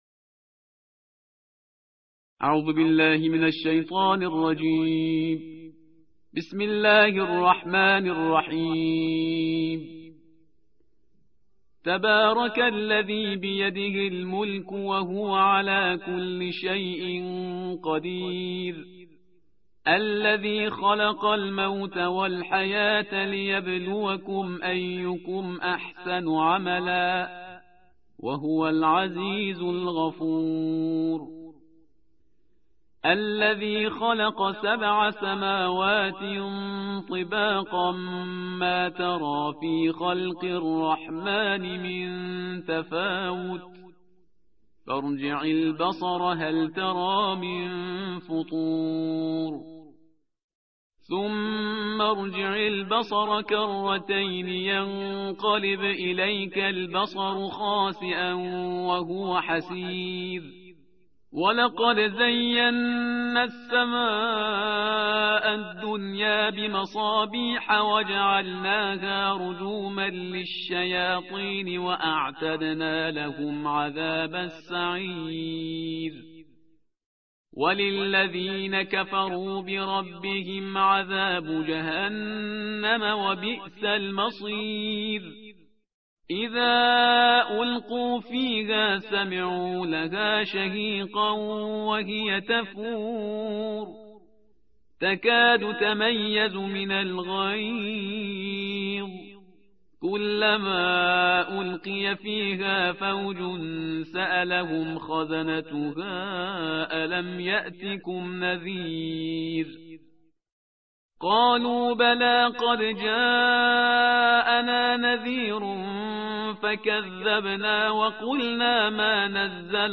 تحدیر و ترتیل جزء 29 همراه با متن تصویری + دانلود mp3
تندخوانی جزء بیست و نهم
تلاوت قرآن کريم